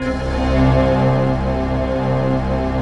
SI1 CHIME00L.wav